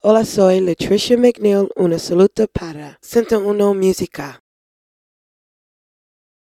23456ce26c94a2ce9e6a4913c8a42bb61b470d69.mp3 Títol 101.1 Música Emissora 101.1 Música Titularitat Privada local Descripció Salutació de la cantant nord-americana, Lutricia McNeal, per 101.1 Música.